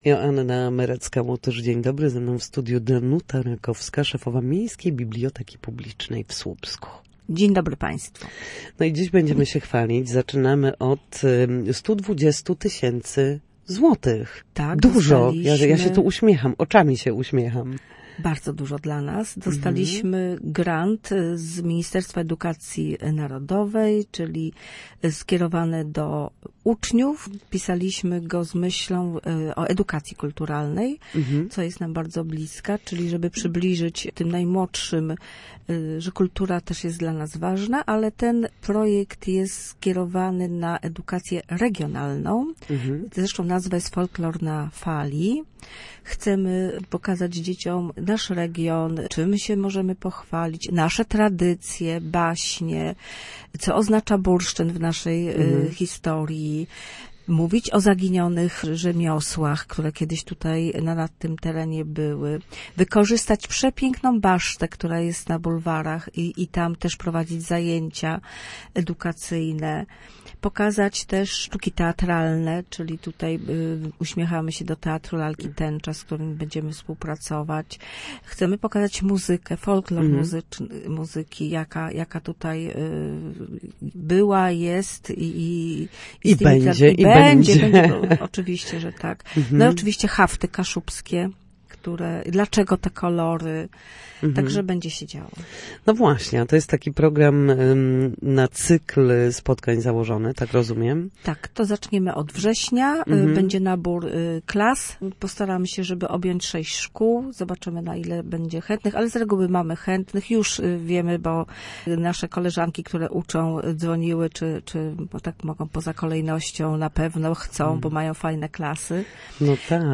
Na antenie Radia Gdańsk opowiadała o nadchodzących wydarzeniach oraz nowym projekcie edukacyjno-kulturalnym.